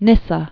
(nĭsə), Saint AD 335?-394?